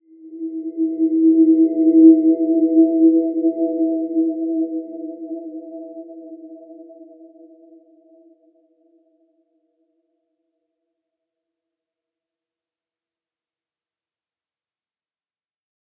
Dreamy-Fifths-E4-mf.wav